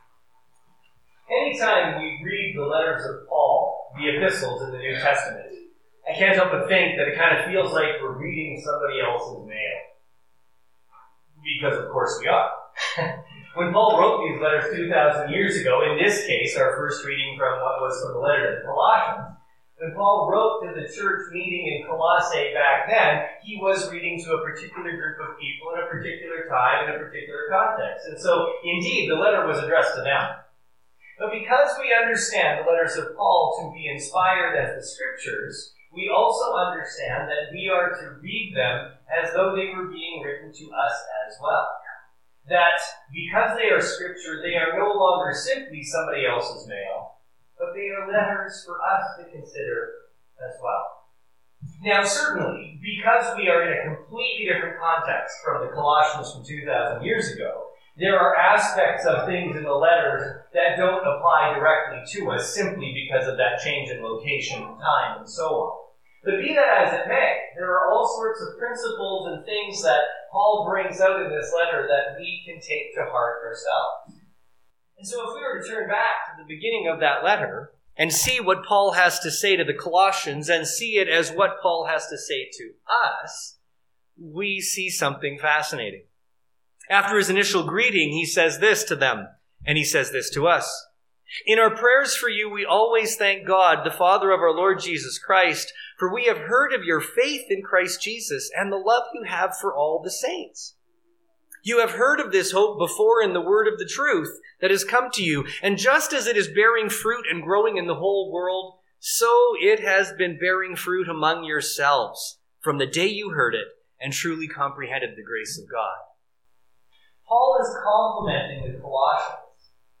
Sermons | The Church of the Good Shepherd
Due to a complete lack of attention on my part, I failed to turn my mic on for the duration of this sermon. So the recording you hear is what got picked up from our lectern mic and represents my best efforts in post-production to get the audio up to an reasonable level for listening.